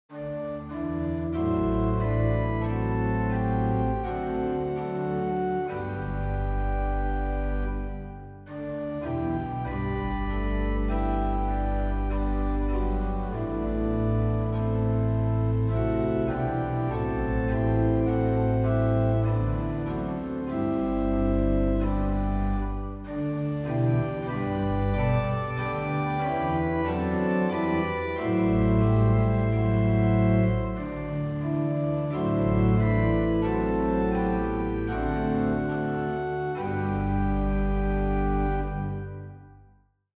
Posnetki z(+) in brez uvoda
in so obrezani za ponavljanje / loop / kitice